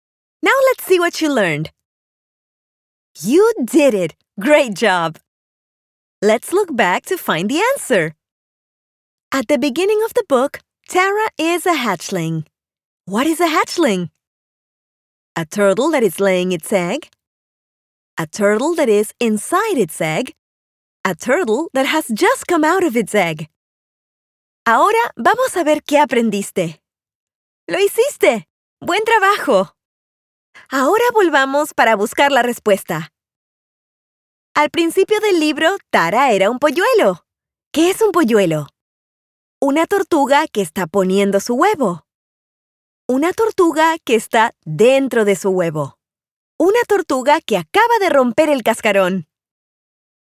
Female
Approachable, Character, Conversational, Corporate, Natural, Warm, Young
e-learning.mp3
Microphone: Manley reference Cardioid